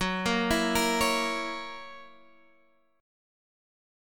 Gb7 Chord
Listen to Gb7 strummed